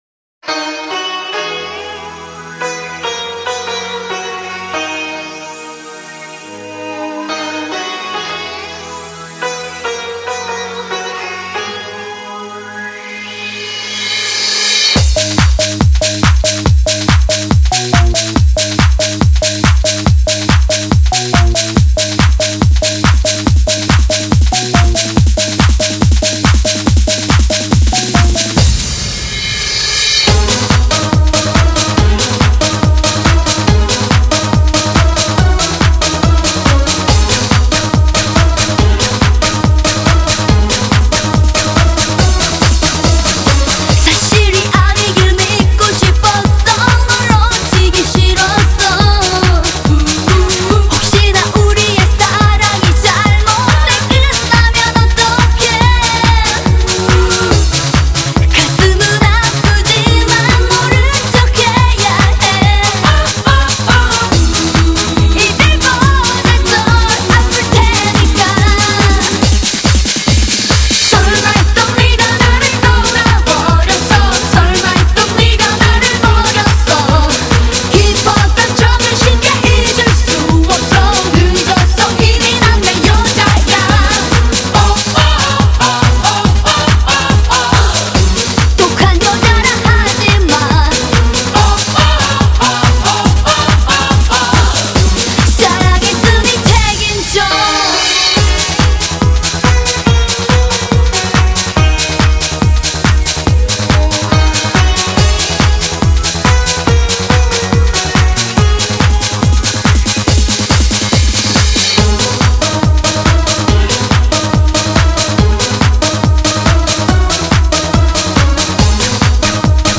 好像是女的